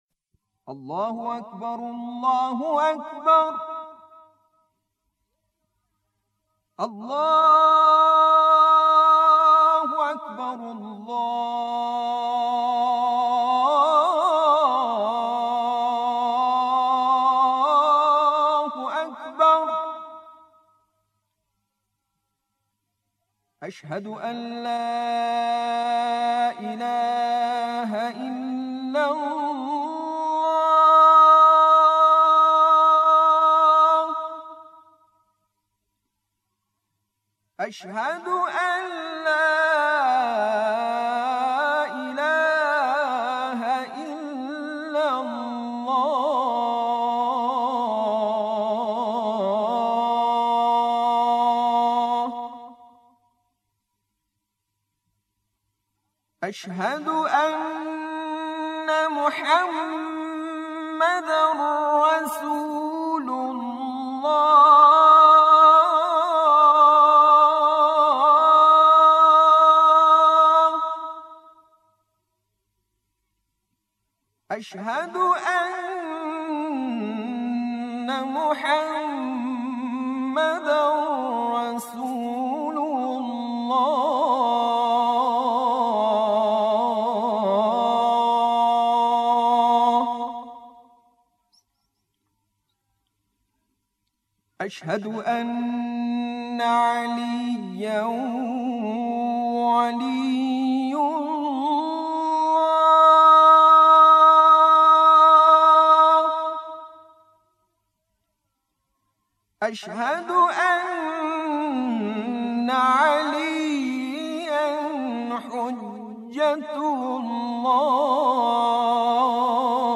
پیشکسوت قرآنی کشور بیان کرد: ایشان پشت سیستم صوتی نشست و میکروفون را نیز در مقابل بنده تنظیم کرد و گفت که اذان بگو.
اذان